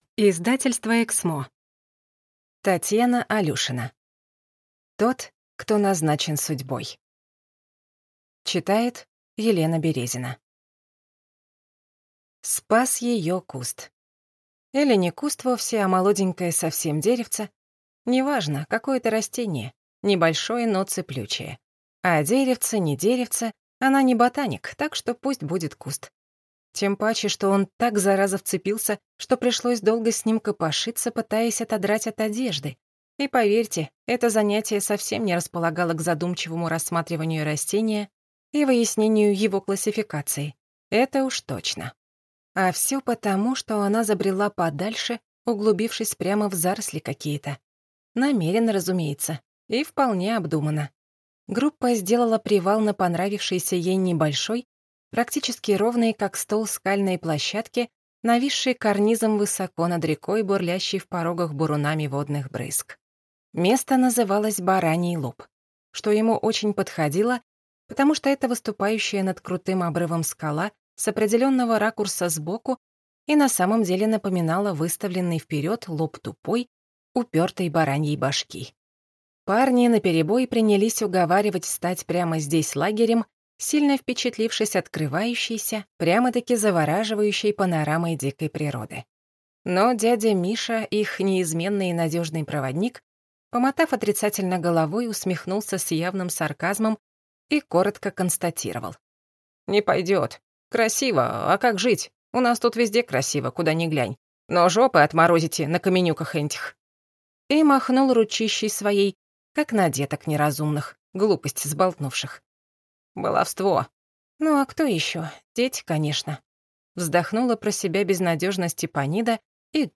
Аудиокнига Тот, кто назначен судьбой | Библиотека аудиокниг